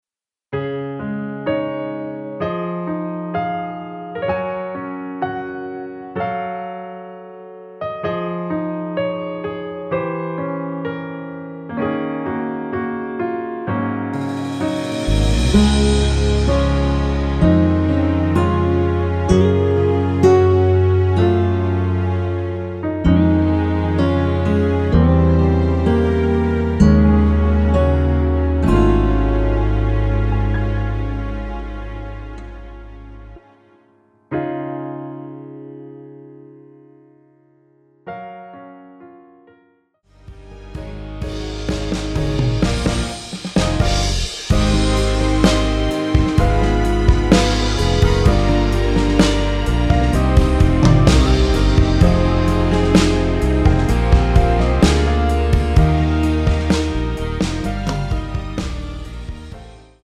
Db
◈ 곡명 옆 (-1)은 반음 내림, (+1)은 반음 올림 입니다.
중간에 음이 끈어지고 다시 나오는 이유는